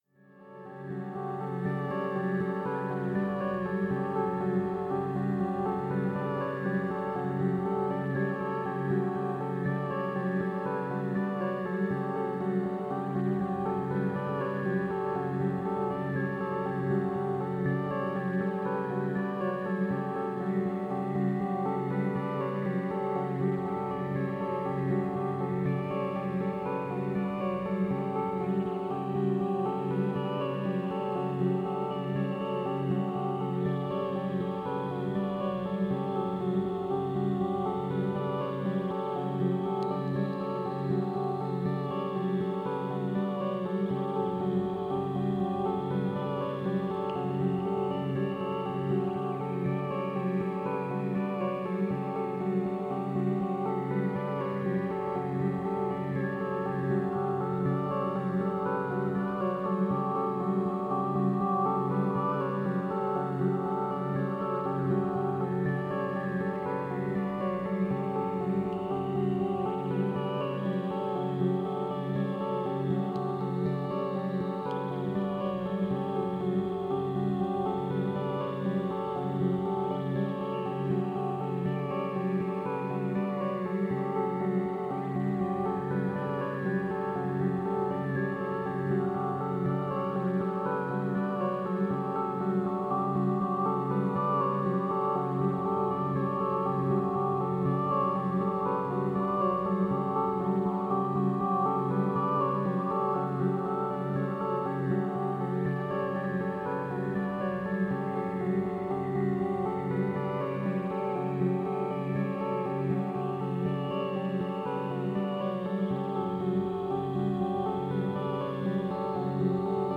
ROLAND D05
2017 mini Boutique version of the legendary classic 1987 Roland D50 synthesizer manufactured to celebrate its 30th year anniversary.
Para Space Ambient
D05-PARASPACEambientArp.mp3